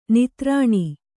♪ nitrāṇi